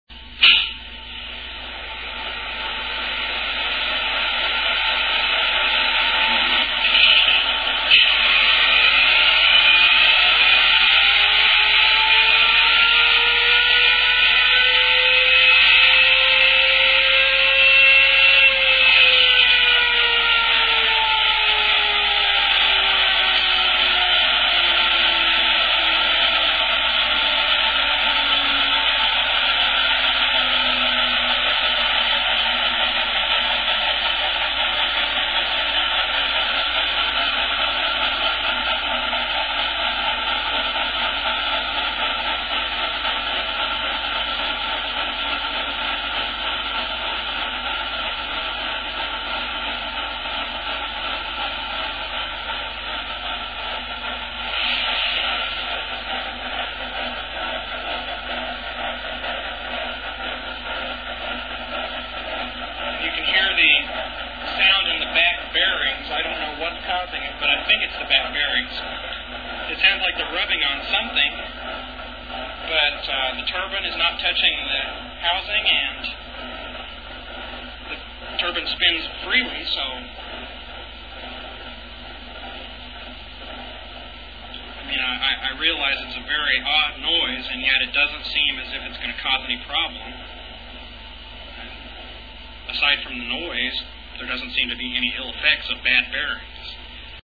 Incluso una vez confeccionado el tablero para el arranque, durante las primeras pruebas con el motor eléctrico auxiliar aparecía un sonido de roce
Después de escucharlo varias veces no nos pareció que el problema fuera un rodamiento en mal estado, sino más bien los sellos del mismo.